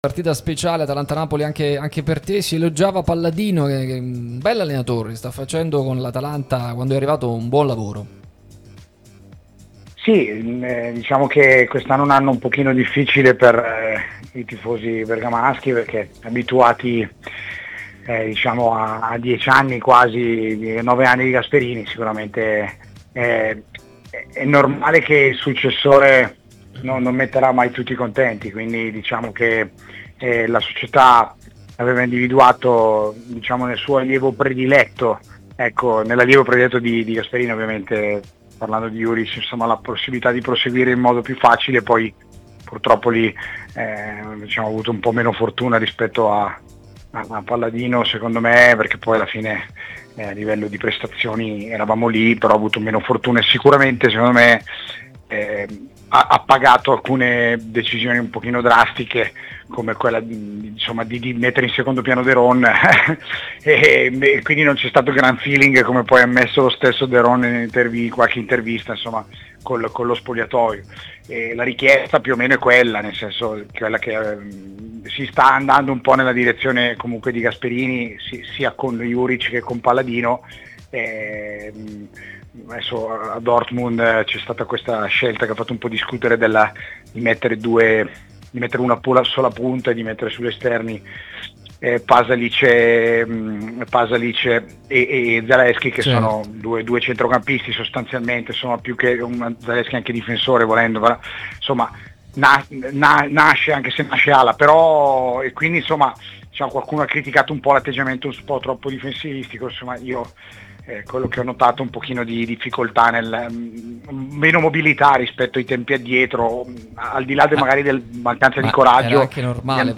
ex attaccante
trasmissione sulla nostra Radio Tutto Napoli, prima radio tematica sul Napoli